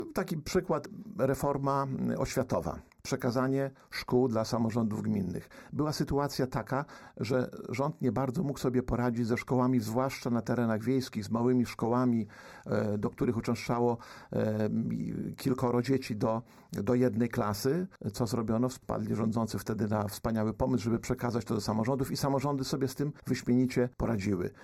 Starosta łomżyński Lech Szabłowski na antenie Radia Nadzieja docenił rolę samorządowców, przyznając, że odpowiadają oni za wiele kluczowych zadań publicznych.